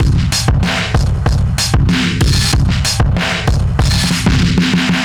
Index of /musicradar/analogue-circuit-samples/95bpm/Drums n Perc
AC_SlackDrumsB_95-97.wav